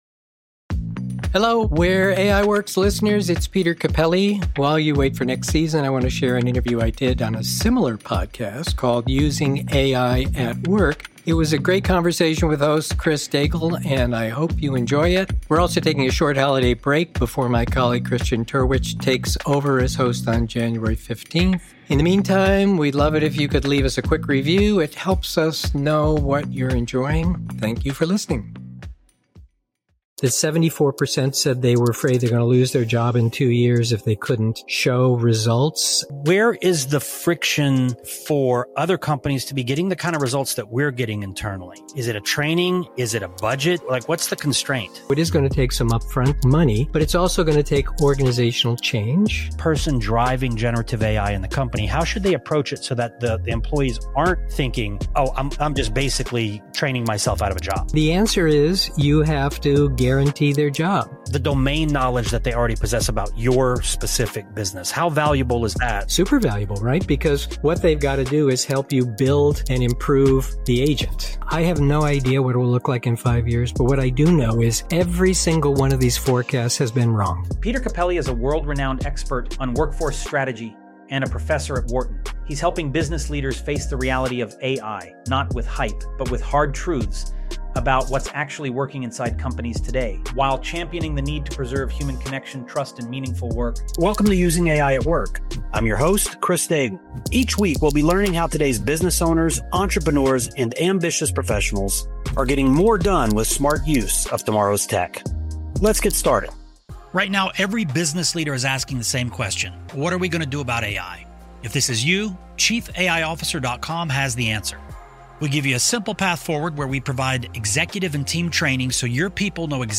Instead of our usual episodes, we’re sharing a special conversation featuring “Where AI Works” season 3 host Peter Cappelli on the podcast “Using AI at Work.” It’s a timely, unfiltered look at how companies are actually trying, and often failing, to implement AI.